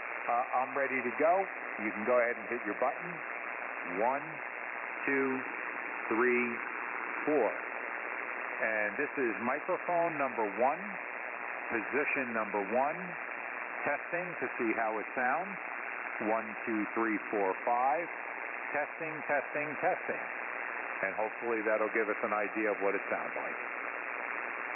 All of the tests were conducted in the HF bands including 80, 40 and 10 meters.
• Mids increased when EQ is on
• Sounds more like my voice in real life with EQ on
Electro Voice RE-320 EQ On
EV-RE-320-EQ-On.mp3